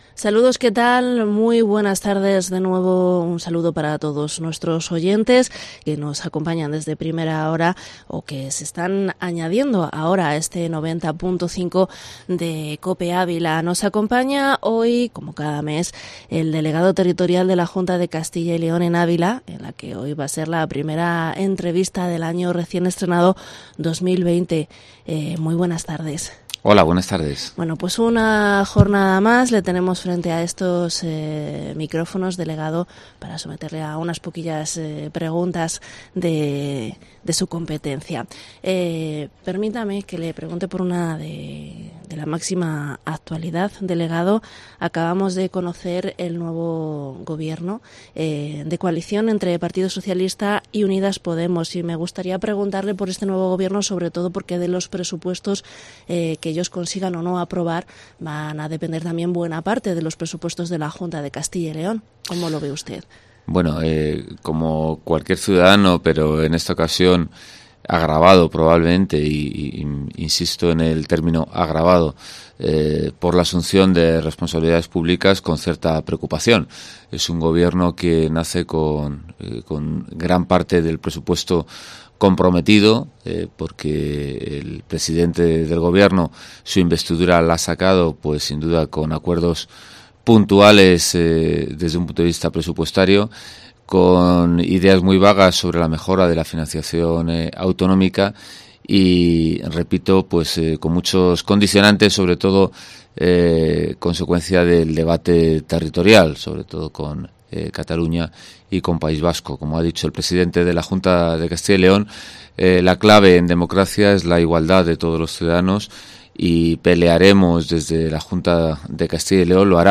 Entrevista en Cope
Entrevista delegado territorial de la Junta en Ávila, José Francisco Hernández Herrero en Mediodía Cope Ávila